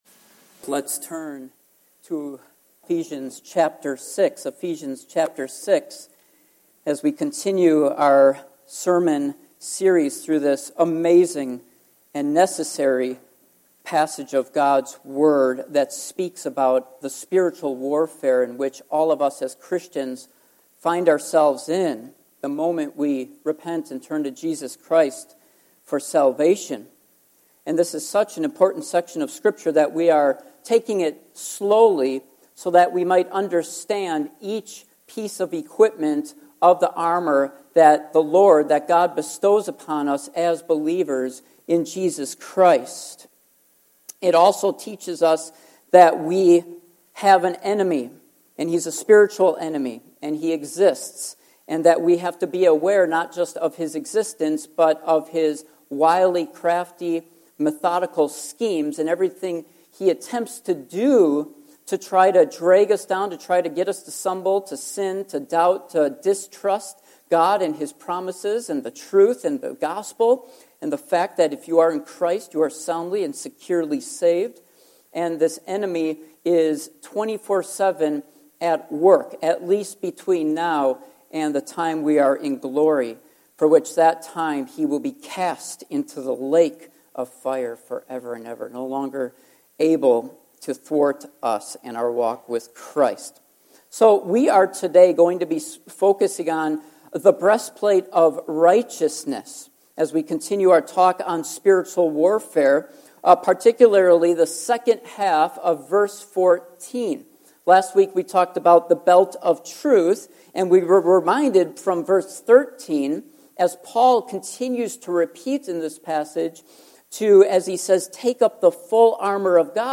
Passage: Ephesians 6:14 Service Type: Morning Worship